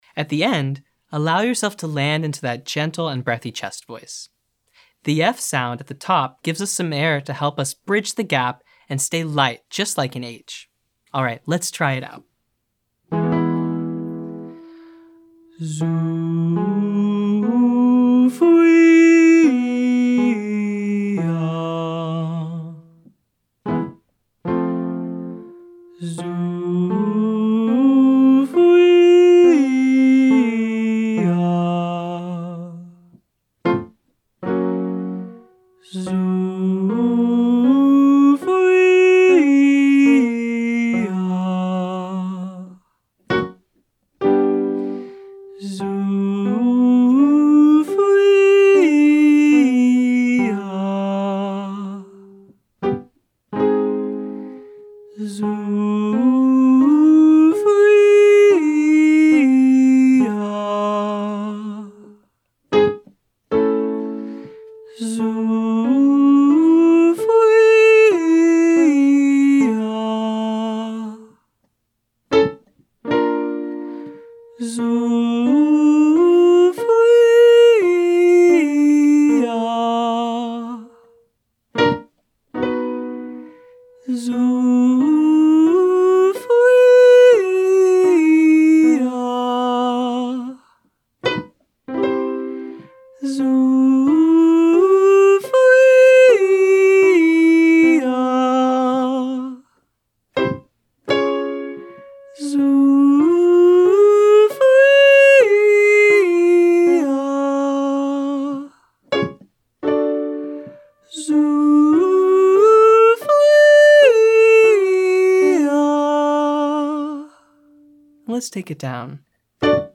The oo keeps our chest voice light and narrow so we can easily transition into more of a head voice dominant mix.